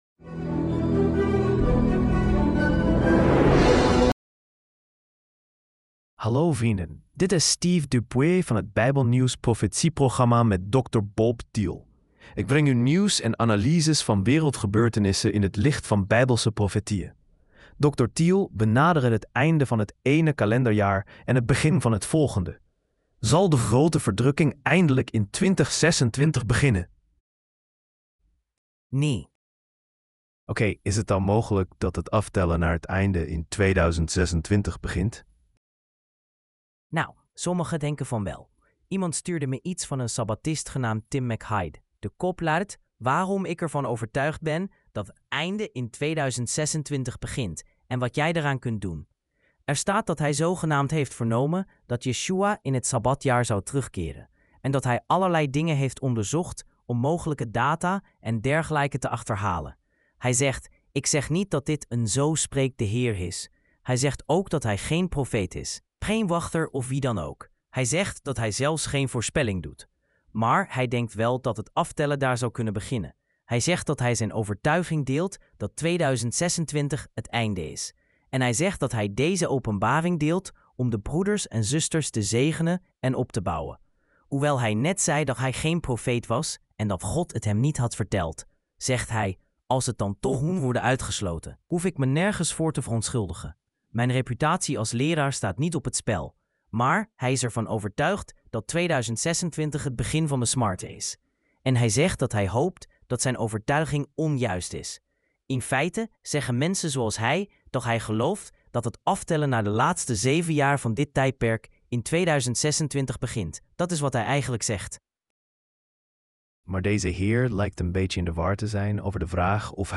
Dutch Sermonette – Bible Prophecy News